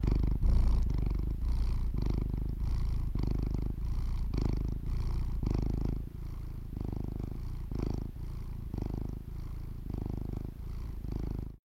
cat-sound
Cat sound - İndir Materyali İndir Bu materyalin etiketi henüz eklenmemiştir.